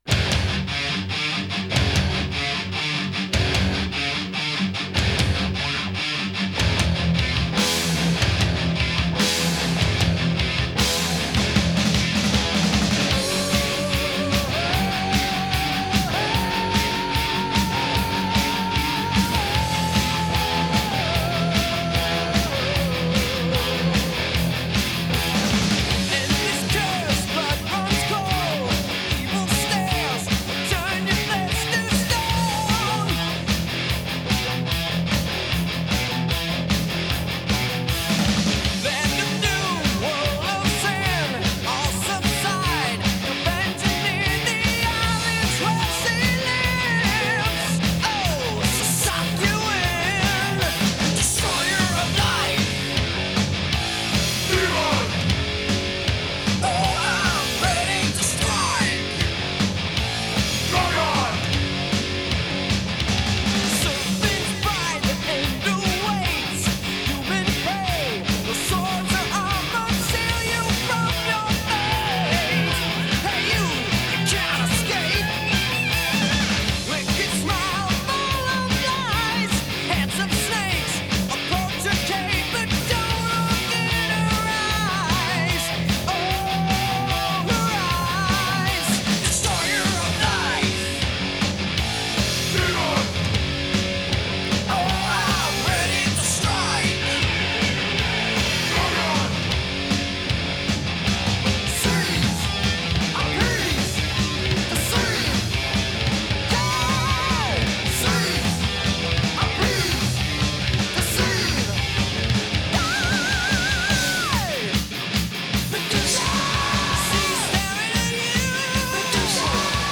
Thrash Metal